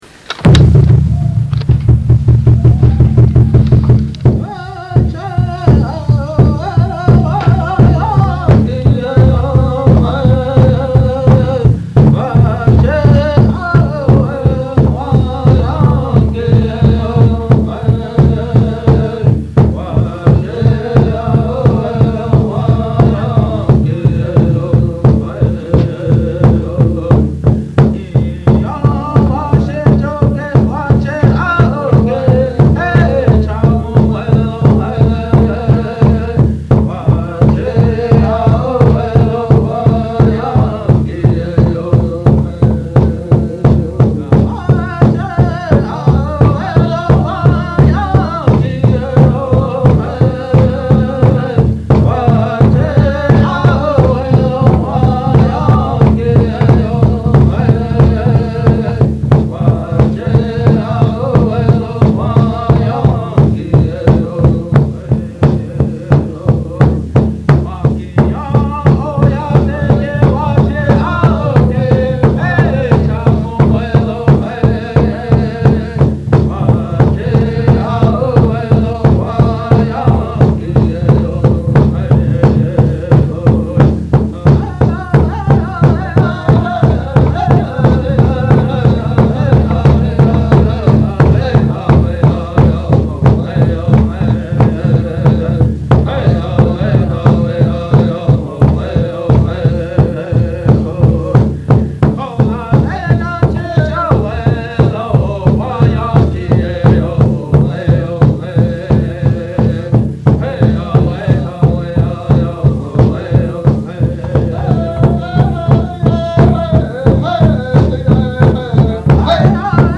Buckskin Drum beater
drum.mp3